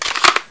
PSP/CTR: Also make weapon and zombie sounds 8bit